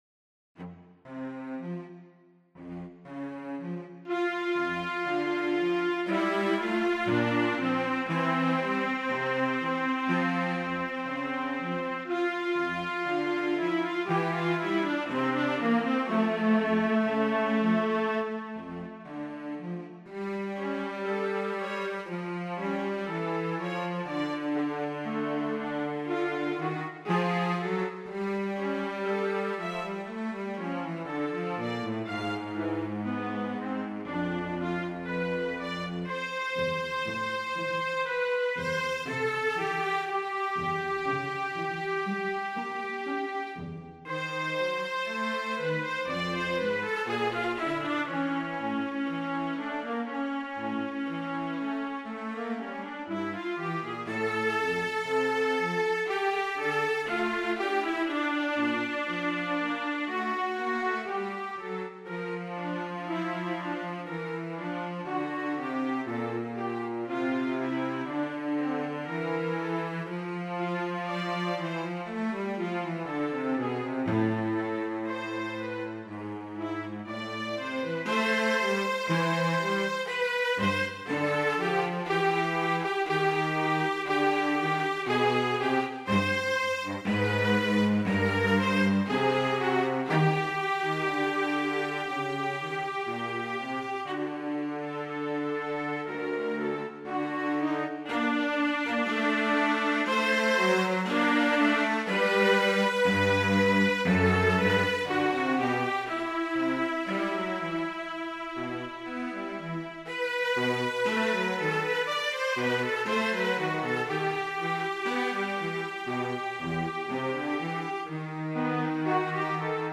Duo for Viola & Cello